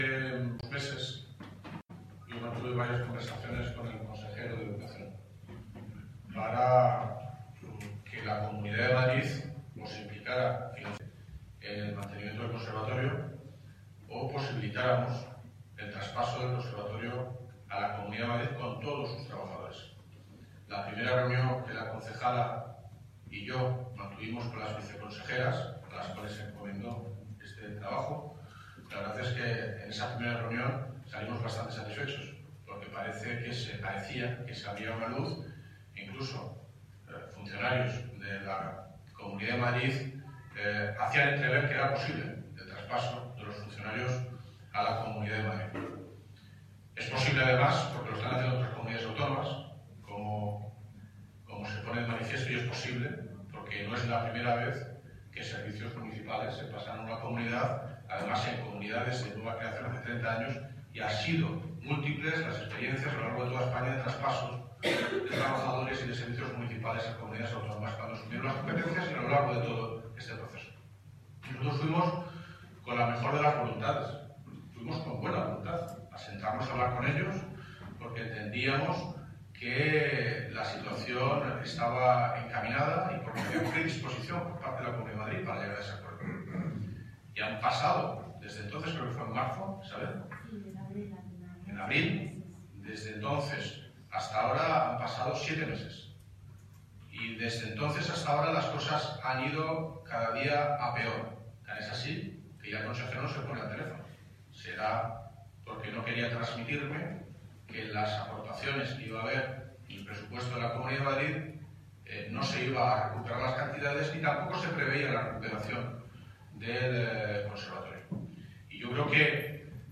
Audio - David Lucas (Alcalde de Móstoles) Sobre el Conservatorio